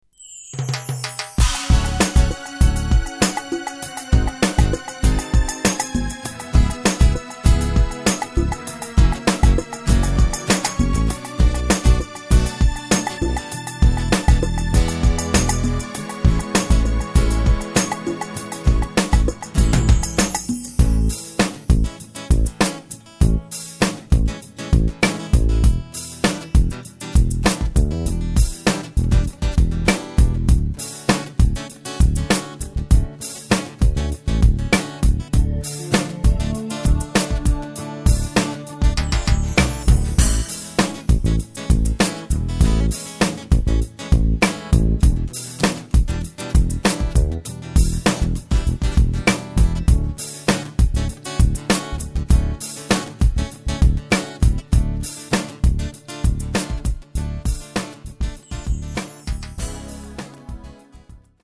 Sax backing tracks in alphabetical order.